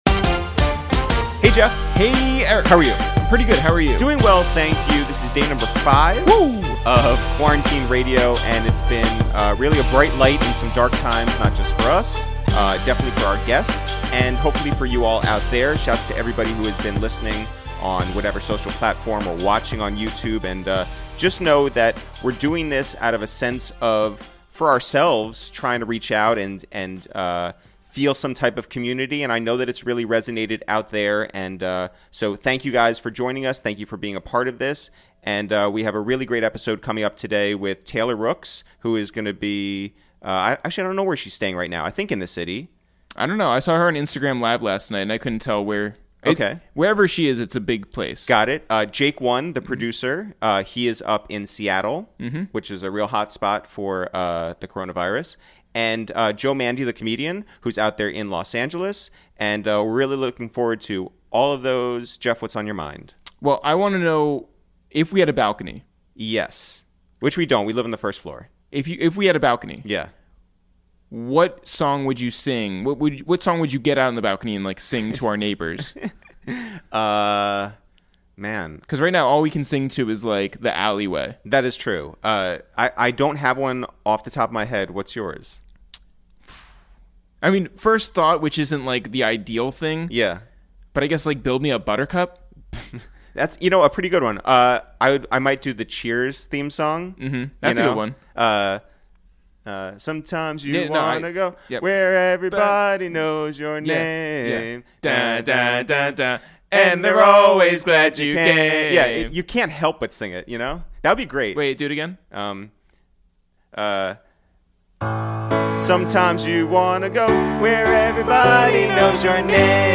Today on Episode 5 of Quarantine Radio, we make calls from our Upper West Side apartment to check in on comedian Joe Mande in LA, producer Jake One in Seattle, and sports journalist Taylor Rooks in downtown NYC.